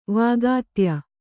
♪ vāgātya